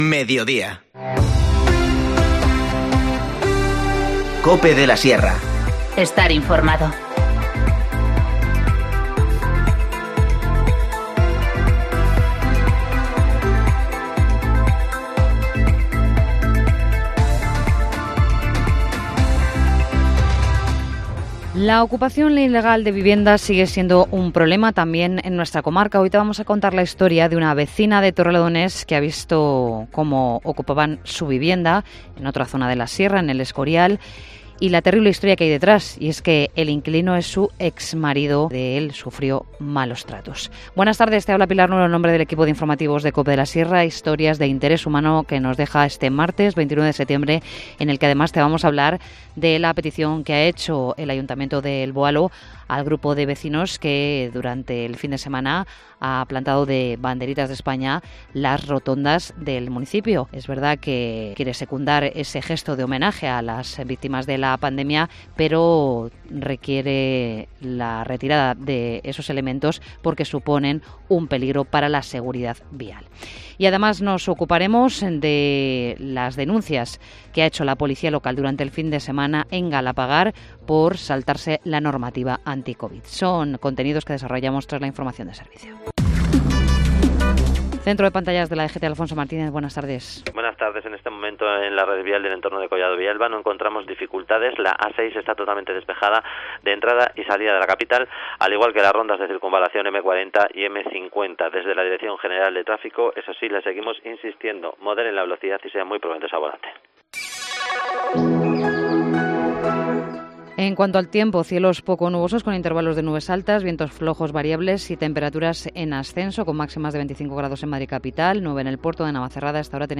Informativo Mediodía 29 septiembre